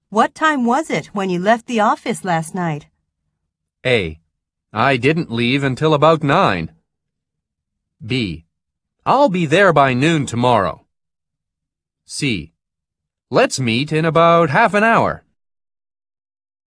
TOEIC音声問題 のアイコンをクリックすると、問い掛けや発言に続いて、それに対する応答が3つ流れます。